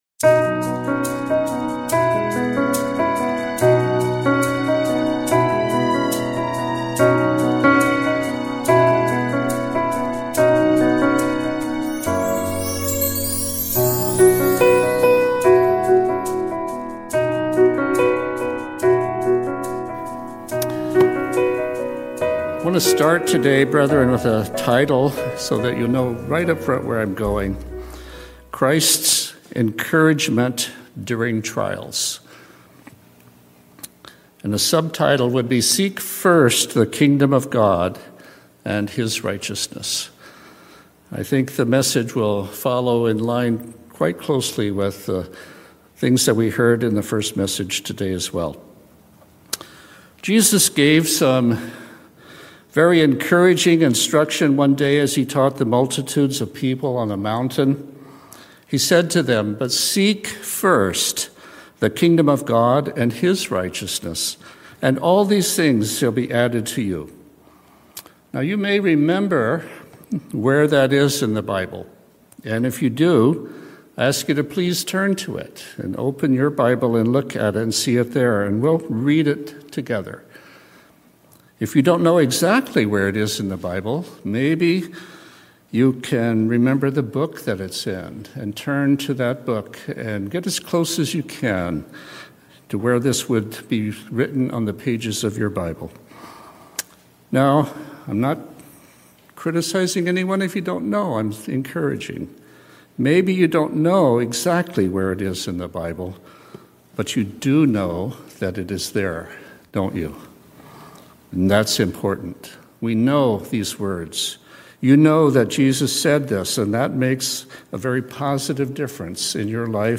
Christ's Encouragement During Trials | United Church of God